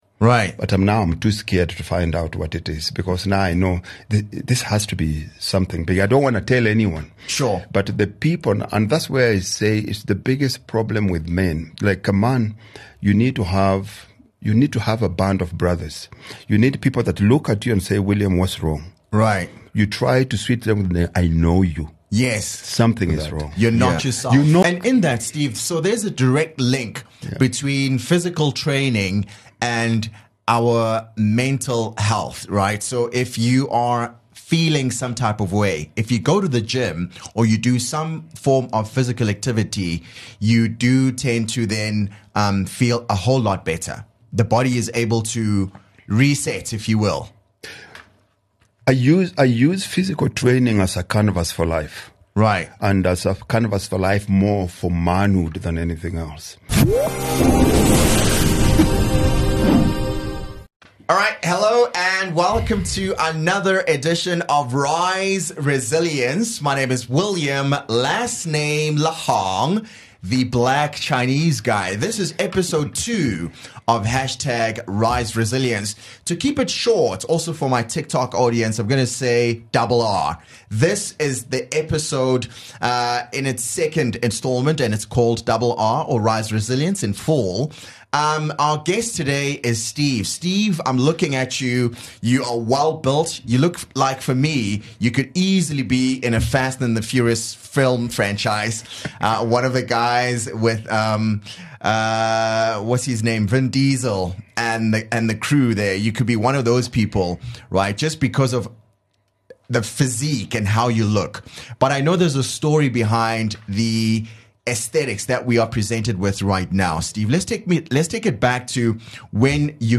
in studio guest